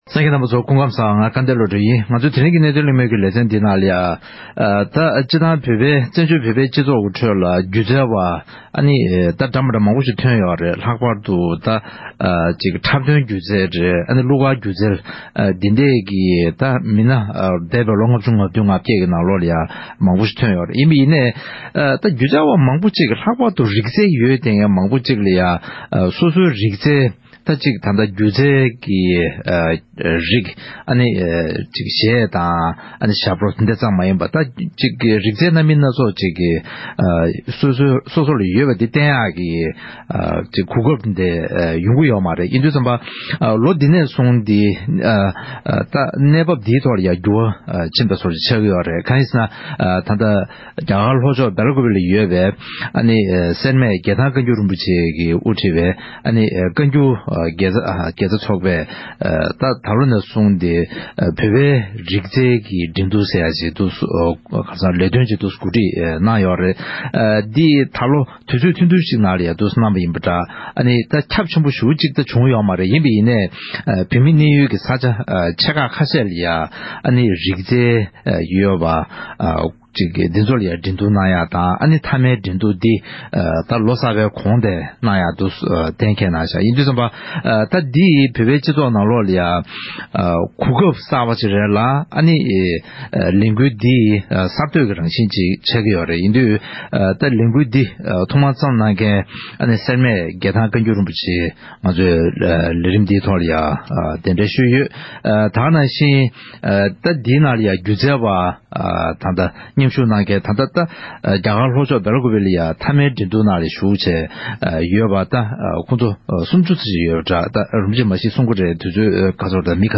དེའི་སྐོར་འབྲེལ་ཡོད་ཁག་གཅིག་དང་གླེང་མོལ་ཞུས་པར་གསན་རོགས་གནང་།